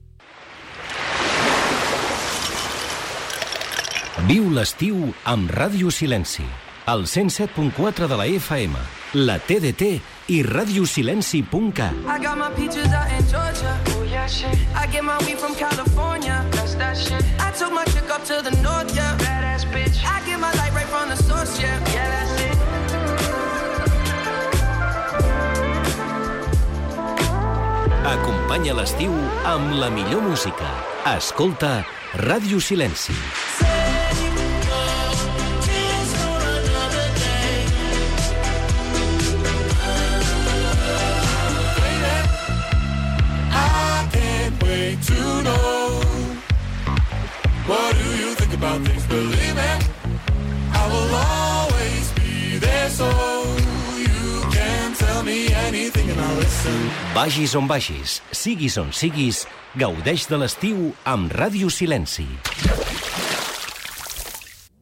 Indicatiu d'estiu.